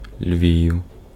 Lviv (/ləˈvv/ lə-VEEV or /ləˈvf/ lə-VEEF; Ukrainian: Львів [ˈlʲwiu̯]
Uk-Львів.ogg.mp3